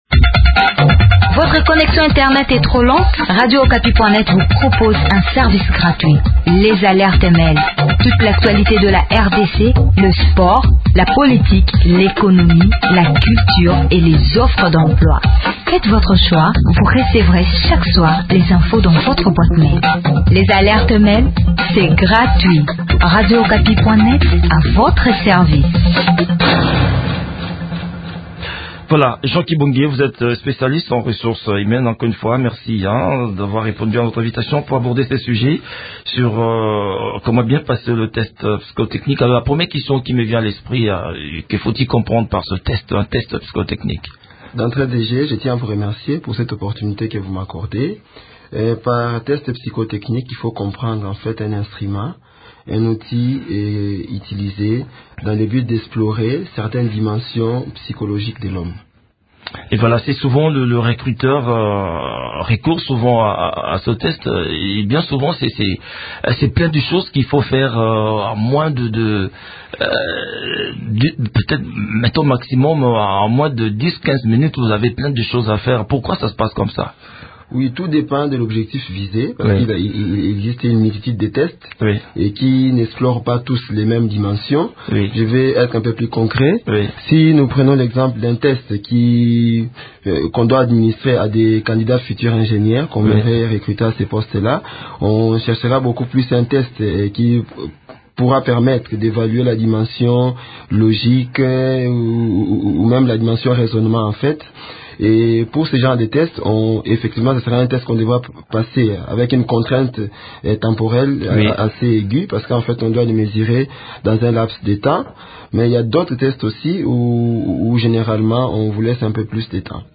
spécialiste en ressources humaines.